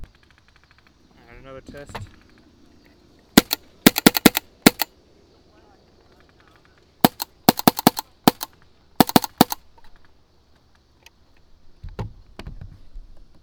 autococker_dry_raw_clipped01.wav